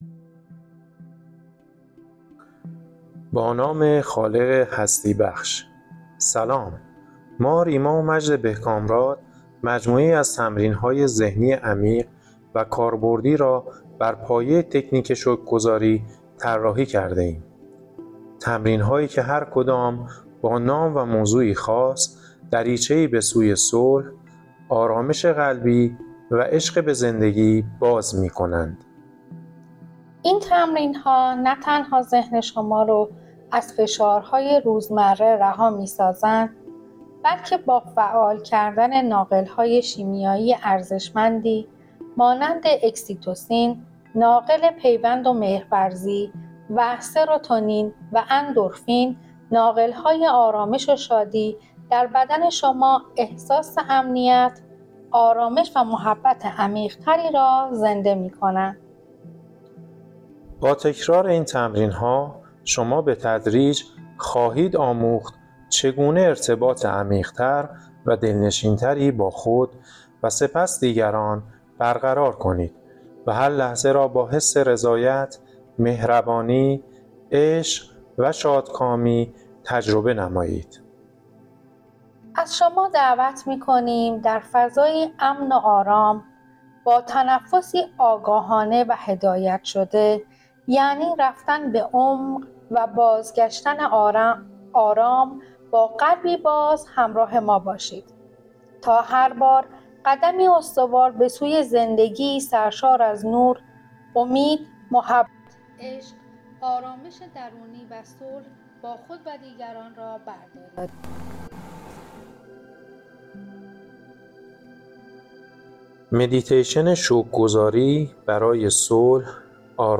این تمرین ۳۰ دقیقه‌ای با موسیقی آرام و جملات شکرگزاری طراحی شده تا: